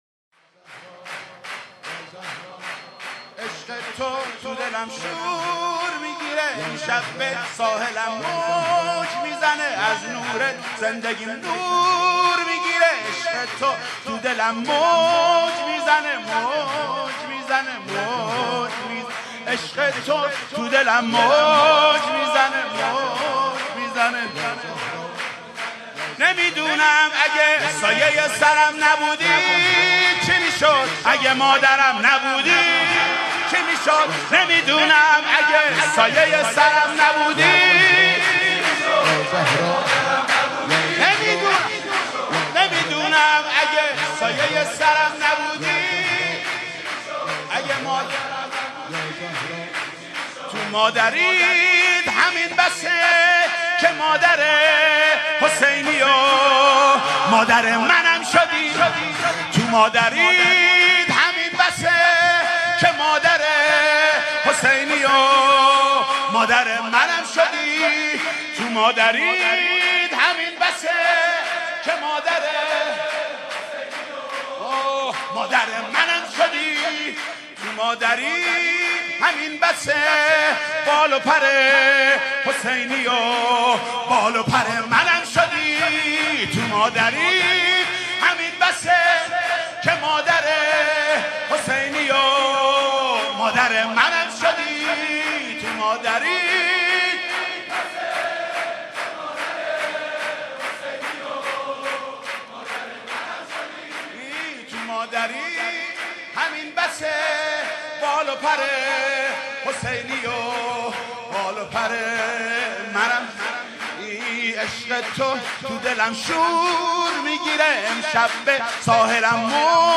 سرود: عشق تو دلم شور میگیره